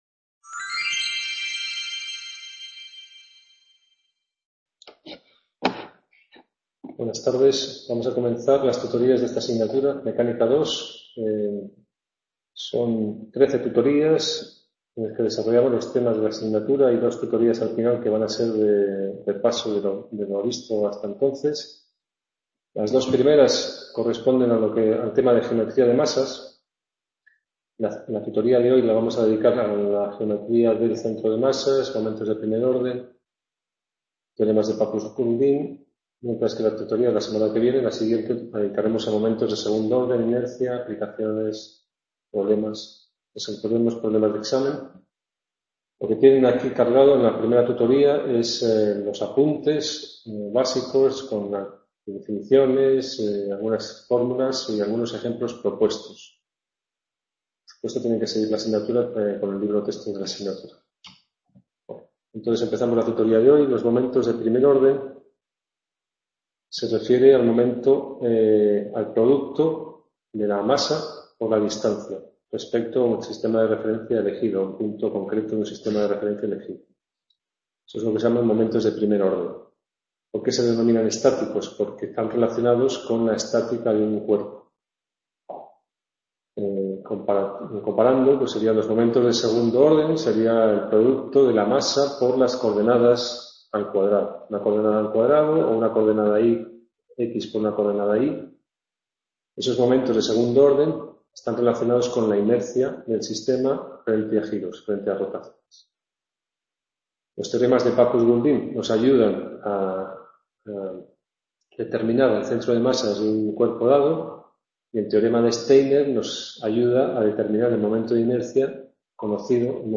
Tutoria 18 Febrero | Repositorio Digital